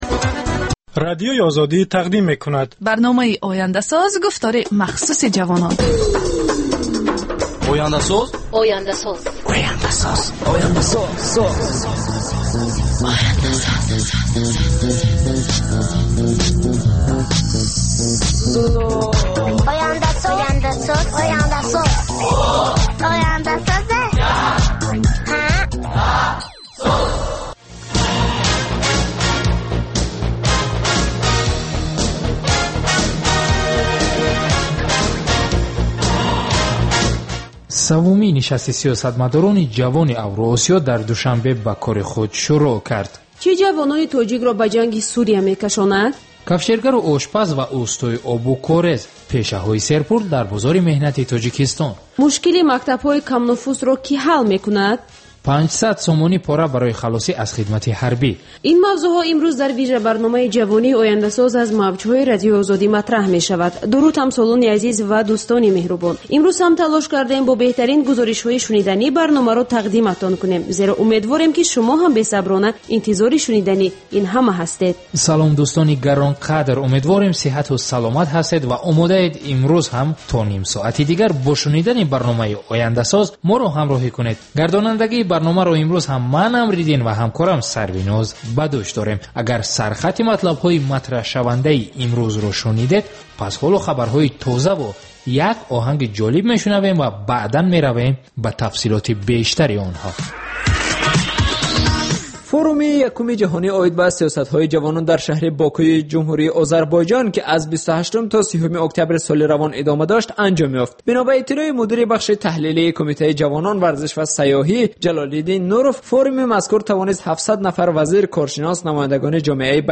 Бар илова, дар ин гуфтор таронаҳои ҷаззоб ва мусоҳибаҳои ҳунармандон тақдим мешавад.